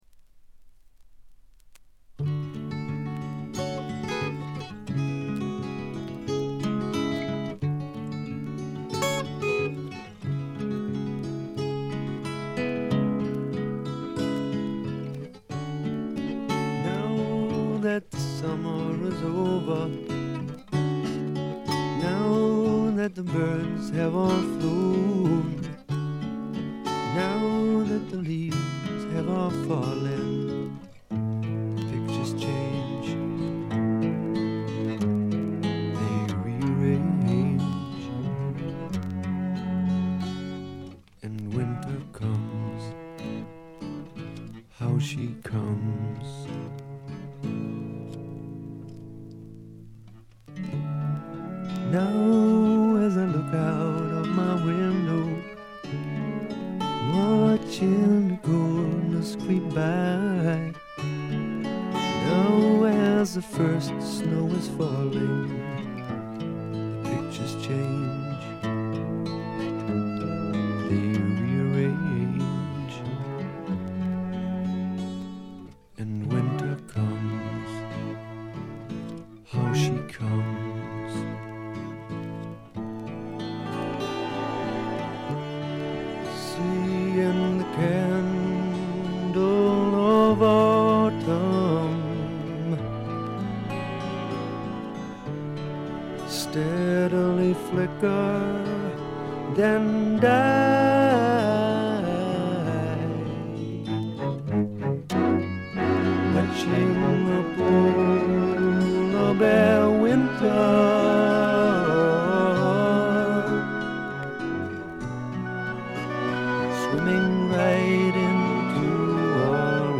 ちょいと鼻にかかった味わい深いヴォーカルがまた最高です。
試聴曲は現品からの取り込み音源です。